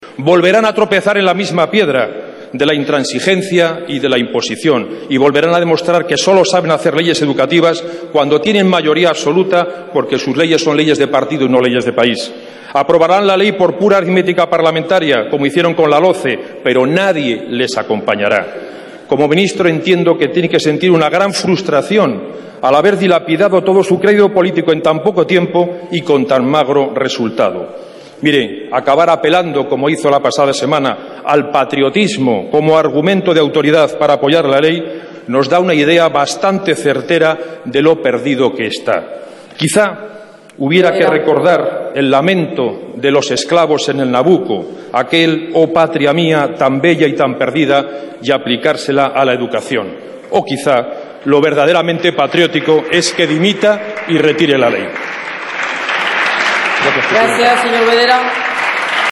Fragmento de la intervención de Mario Bedera en el Congreso durante el debate del proyecto de ley Wert 28/11/2013